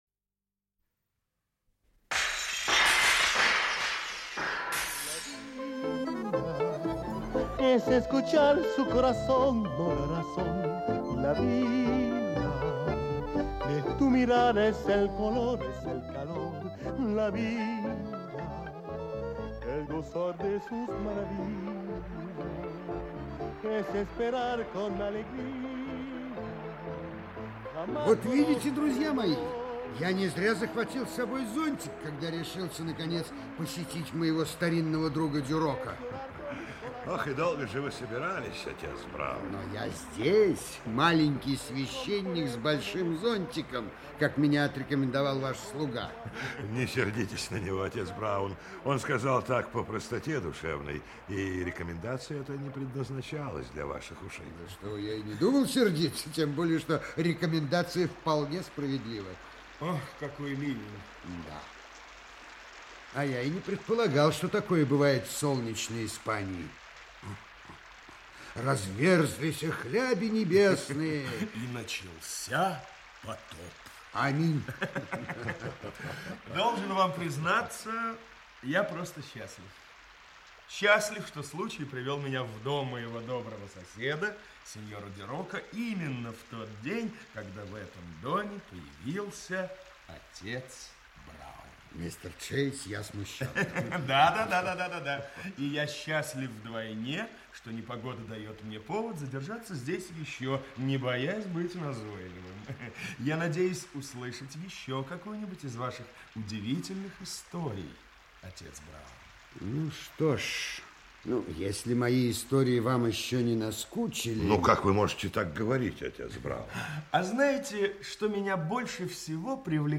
Аудиокнига Злой рок семьи Дарнуэй. Часть 1 | Библиотека аудиокниг
Часть 1 Автор Гилберт Кит Честертон Читает аудиокнигу Актерский коллектив.